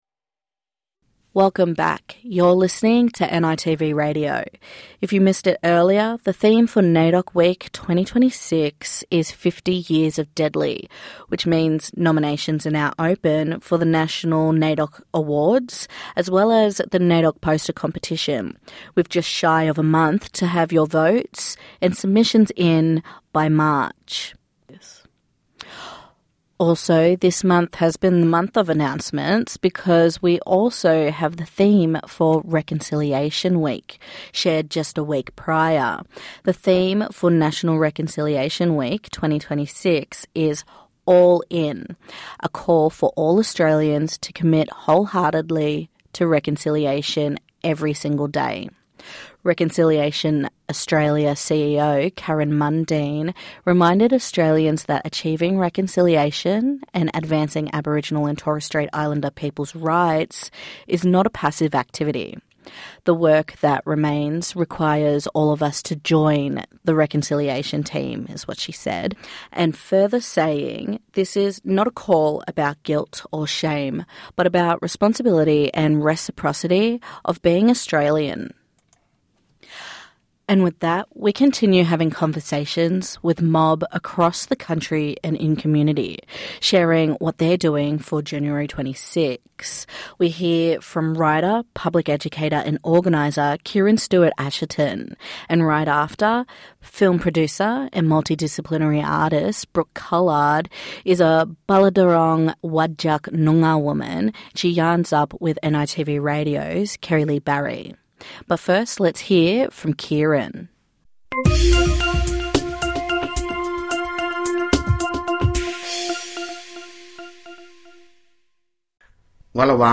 NITV Radio have conversations with mob in community from across Australia.